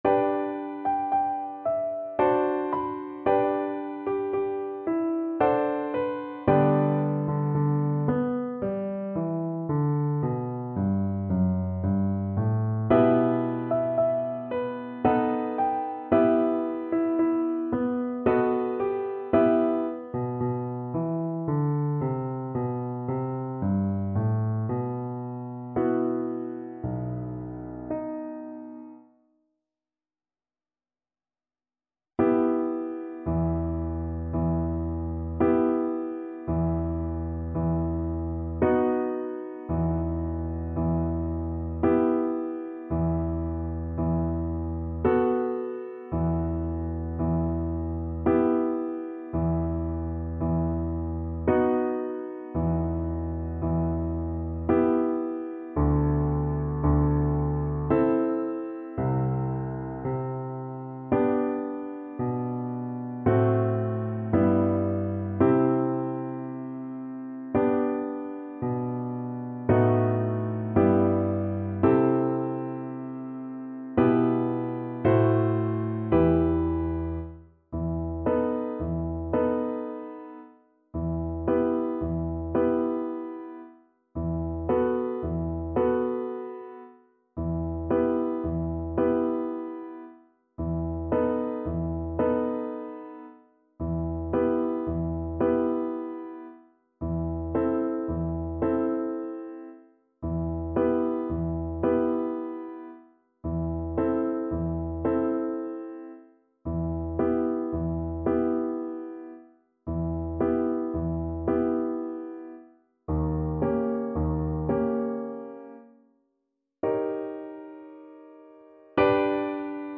3/4 (View more 3/4 Music)
~ = 56 Andante
Classical (View more Classical Viola Music)